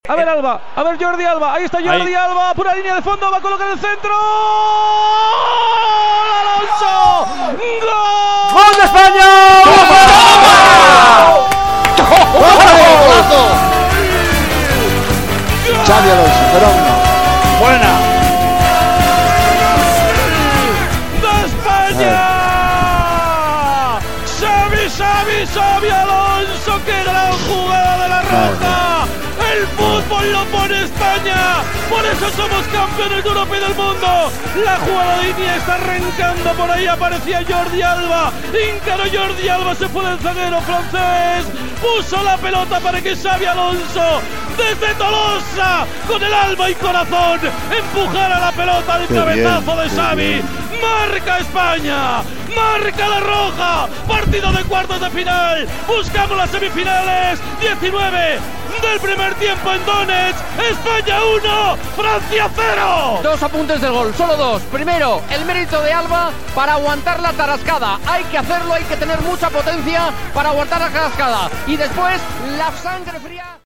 Narració des del Donbass Arena Donetsk (Ucraïna) del gol de Xabi Alonso i reconstrucció de la jugada en el partit entre les seleccions d'Espanya i França en els quarts de final de l'Eurocopa de Futbol masculí.
Esportiu